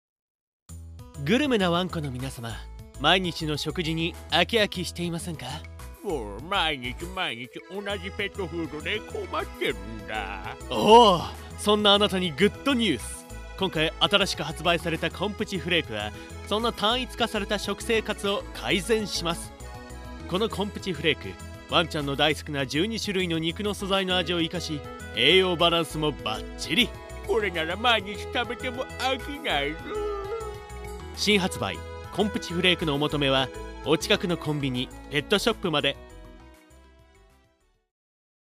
ペットフードのCM（青年・犬）
映画予告（中年・青年）